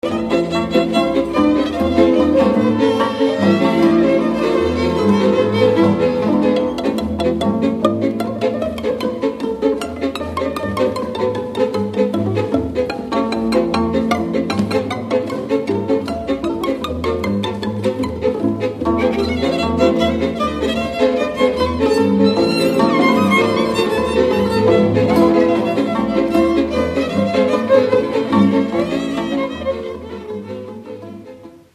Dallampélda: Hangszeres felvétel
Dunántúl - Fejér vm. - Perkáta
vonós zenekar
Műfaj: Ugrós
Stílus: 5. Rákóczi dallamkör és fríg környezete